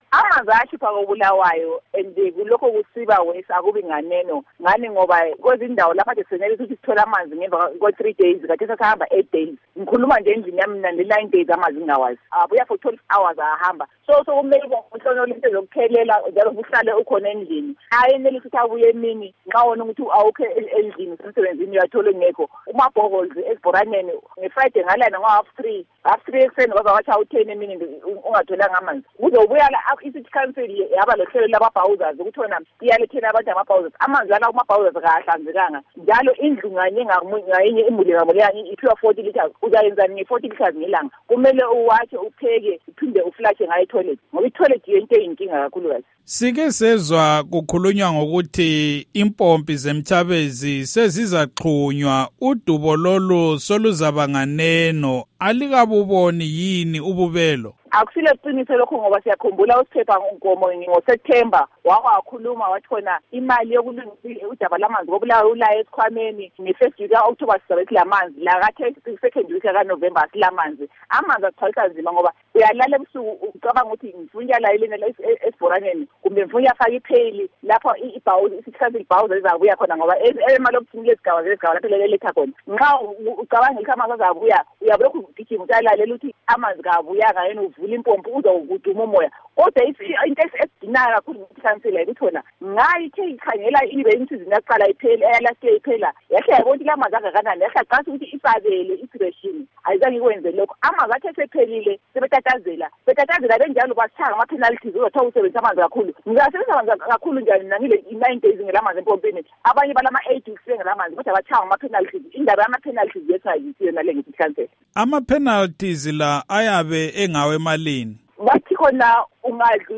Ingxoxo loNkosazana Magodonga Mahlangu